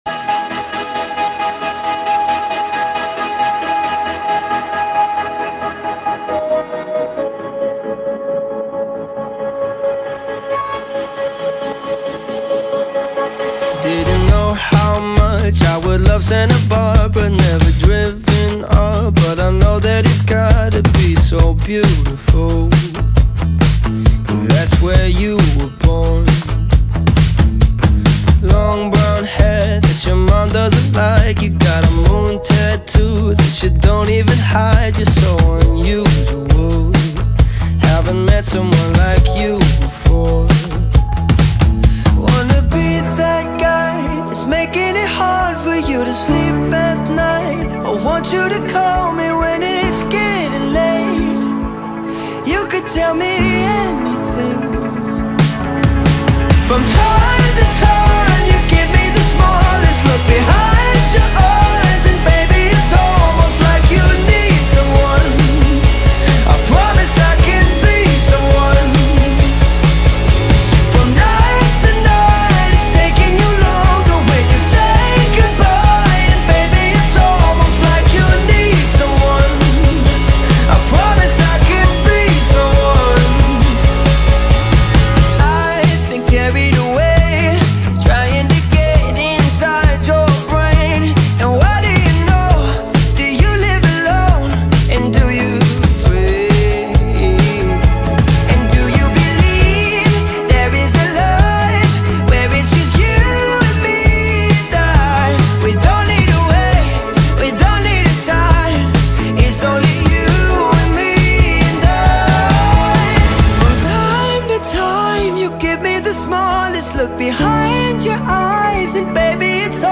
Pop Rock, Pop